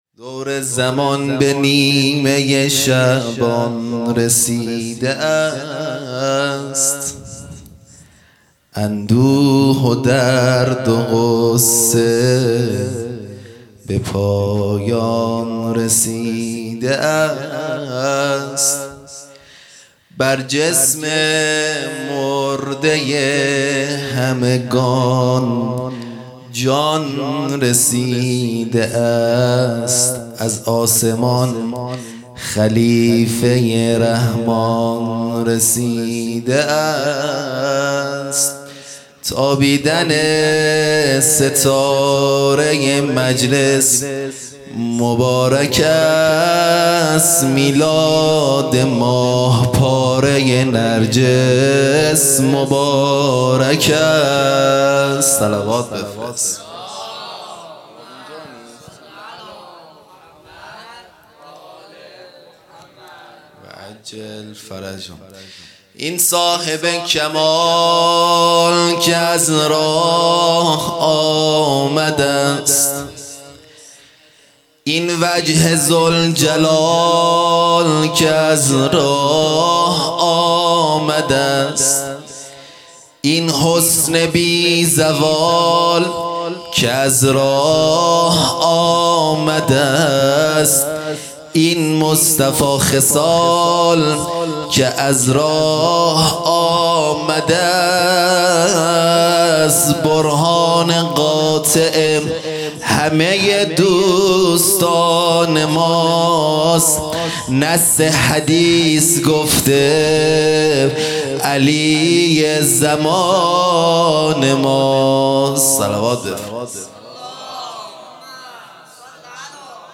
خیمه گاه - هیئت بچه های فاطمه (س) - مدح | دور زمان به نیمۀ شعبان رسیده است
جشن نیمۀ شعبان